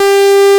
MINI WVFORM.wav